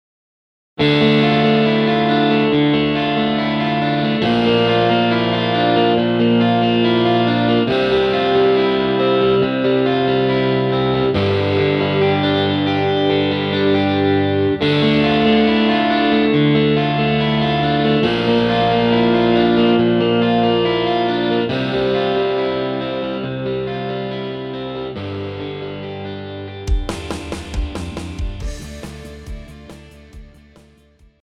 Žánr: Rock
BPM: 138
Key: G
MP3 ukázka